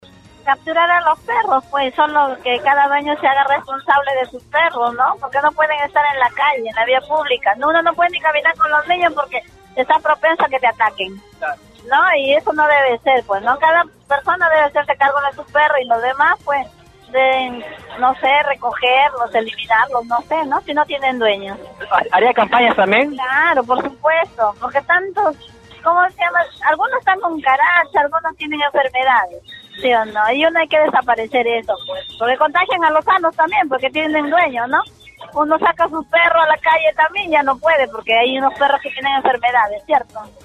Debido a la gran cantidad de perros en las calles de Villa El Salvador, vecinos opinaron a través de radio Stereo Villa respecto a las alternativas que deberían plantearse para reducir el incremente de canes que deambulan por el distrito.
Una vecina del distrito, aseguró que los perros callejeros podrían generar posibles enfermedades a la comunidad.